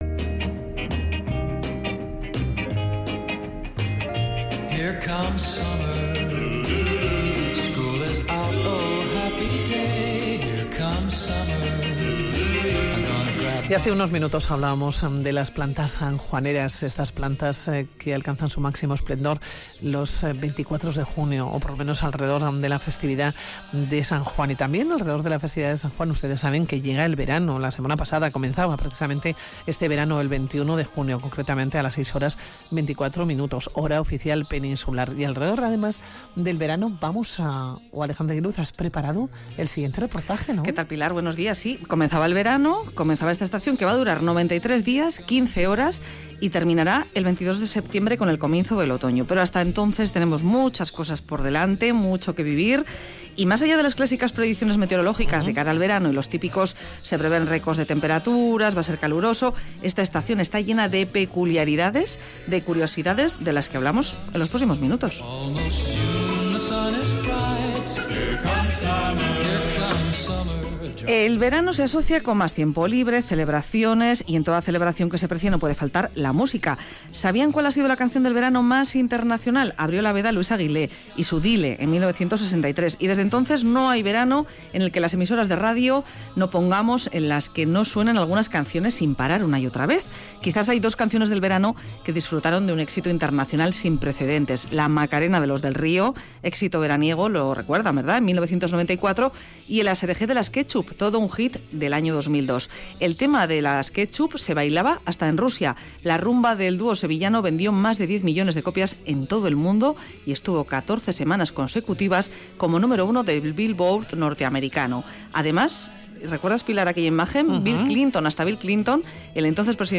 Radio Vitoria| Reportaje sobre curiosidades del verano. Canciones, comidas y preculiaridades de esta estación que nos acompaña desde el 21 de junio.